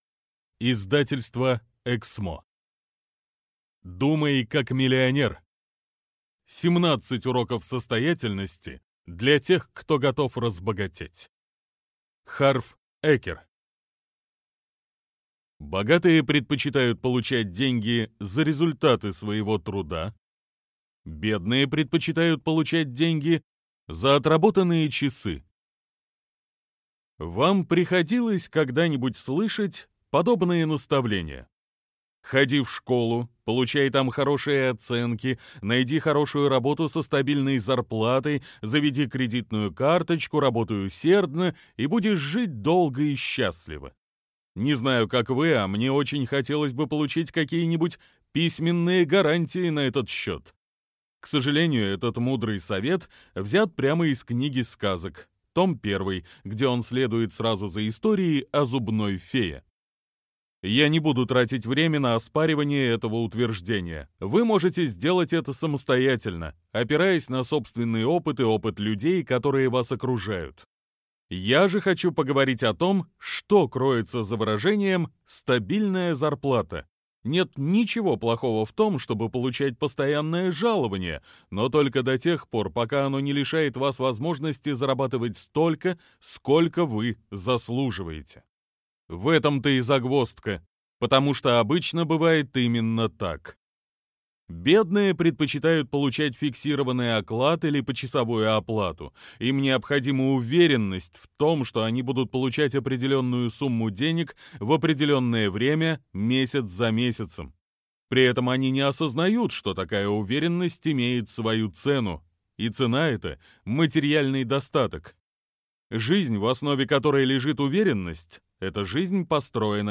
Аудиокнига Думай как миллионер. 17 уроков состоятельности для тех, кто готов разбогатеть | Библиотека аудиокниг
Прослушать и бесплатно скачать фрагмент аудиокниги